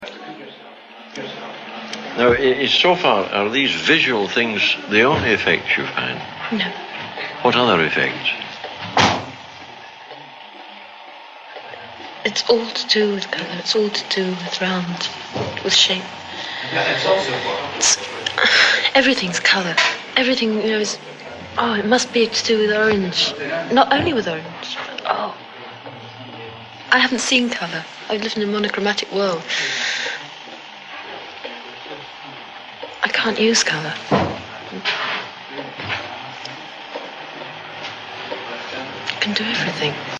Mp3 Sound Effect In a quiet 1950s lab, a young woman becomes one of the earliest participants in an LSD study and tries to describe the visual effect of the compound.